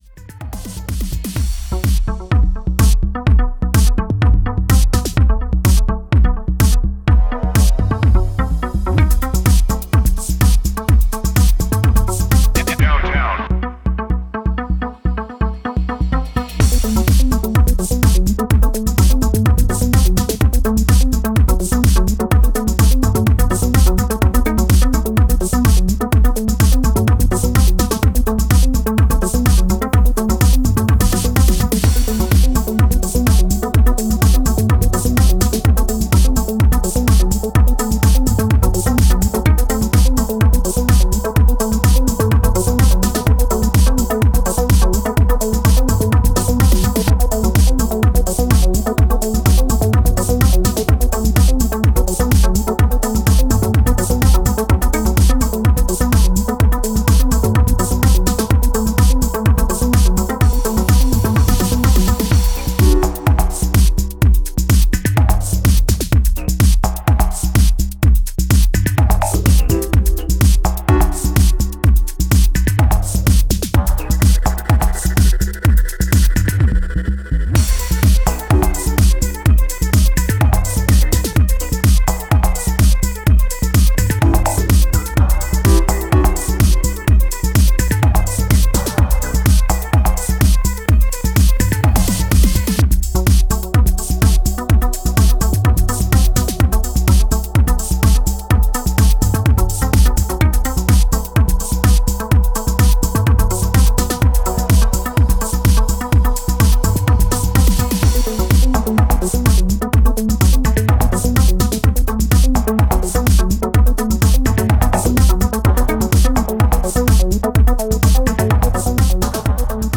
バウンシーなグルーヴが心地良い、エレクトロ・ディープ・ハウス